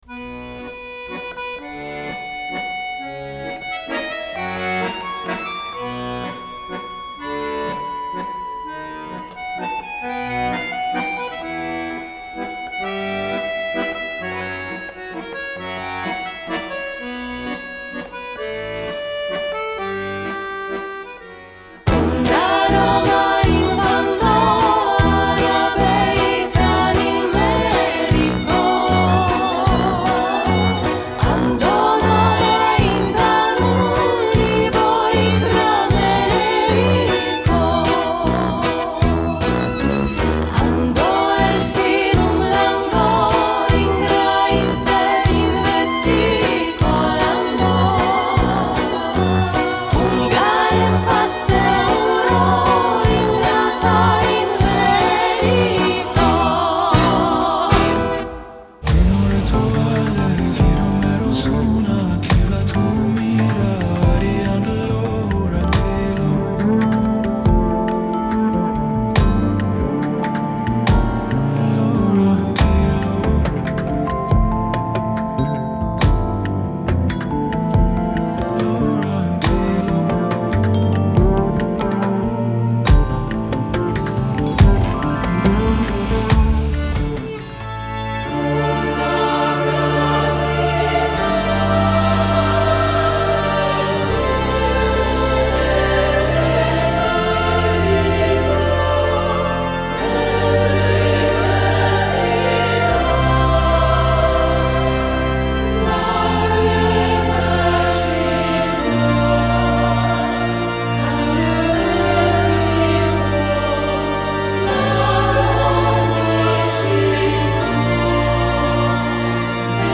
Gesang
Violine
Akkordeon/Orgel
Gitarre
Kontrabass/Fretless Bass
Audio-Mix